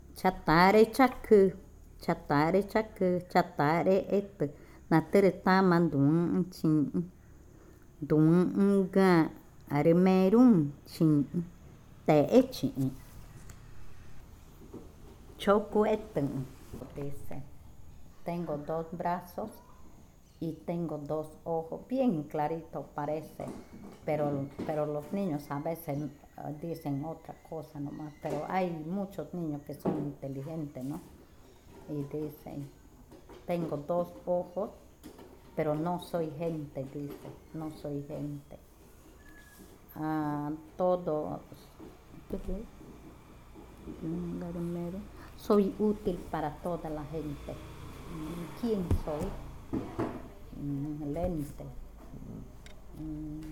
Adivinanza 5. Los lentes
Cushillococha